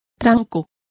Complete with pronunciation of the translation of stride.